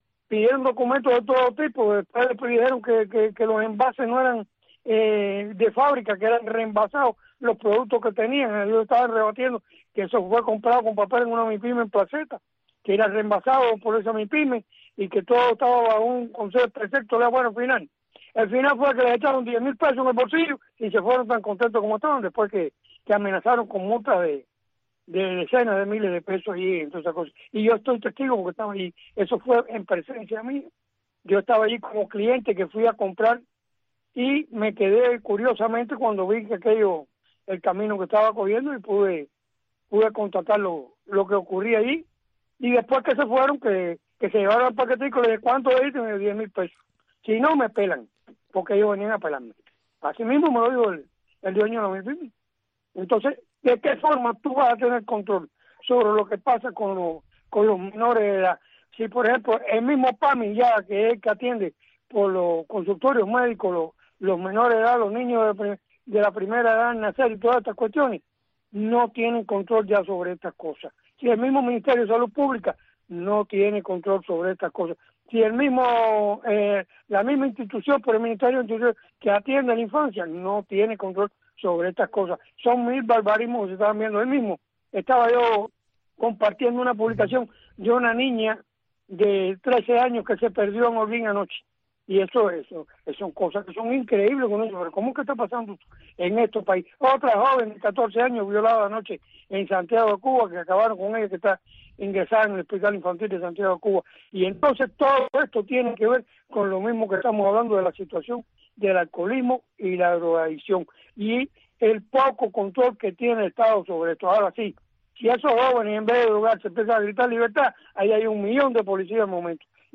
conecta cada día con sus invitados en la isla en este espacio informativo en vivo